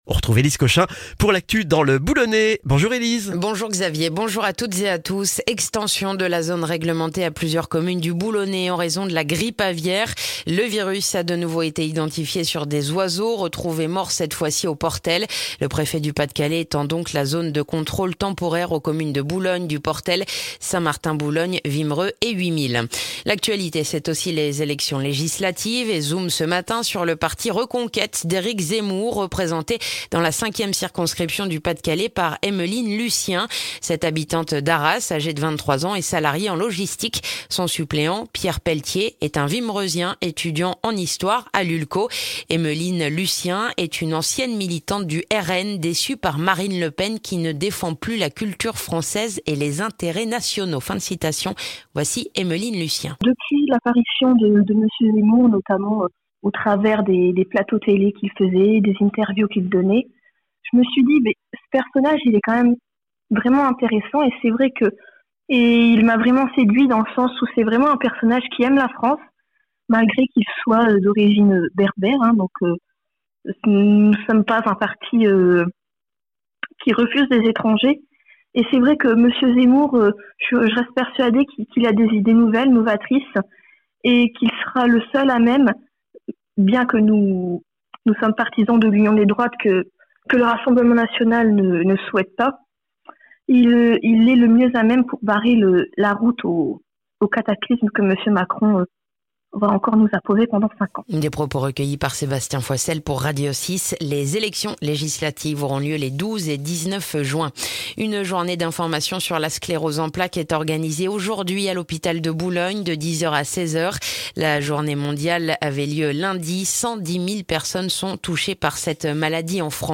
Le journal du jeudi 2 juin dans le boulonnais